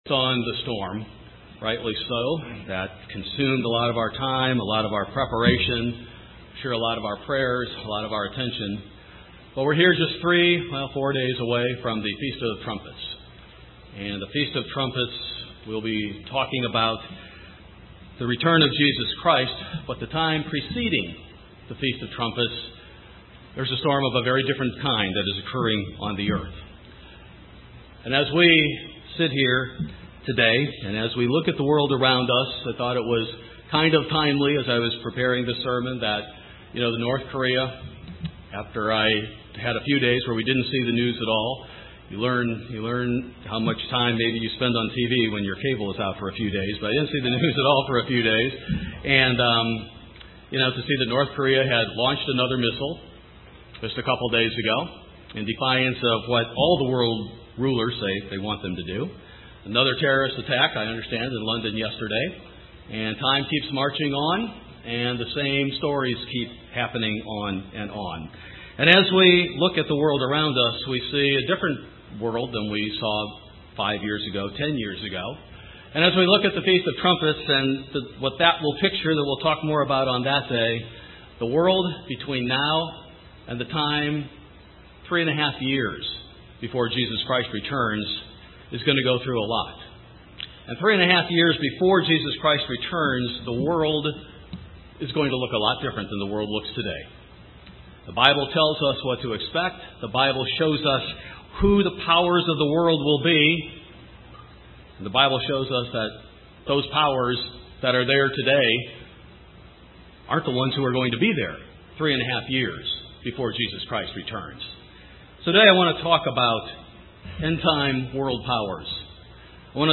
End Time World Powers | United Church of God